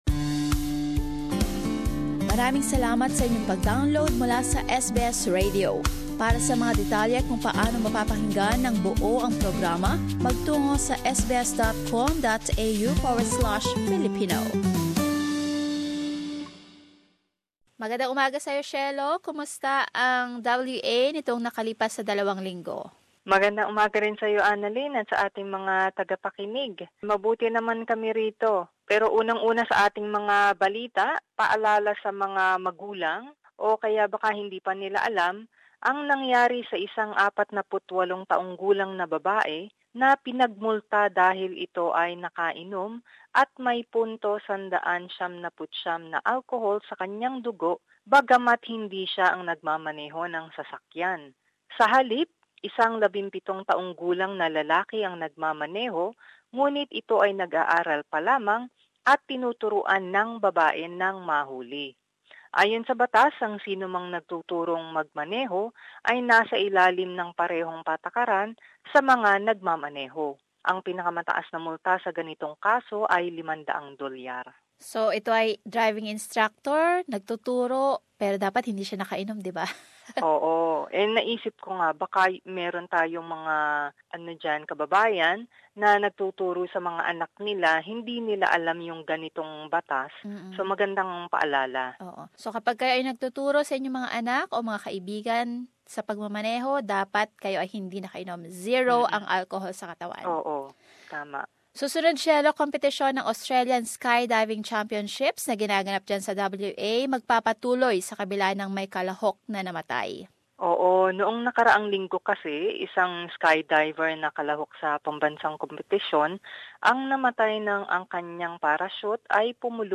Perth report.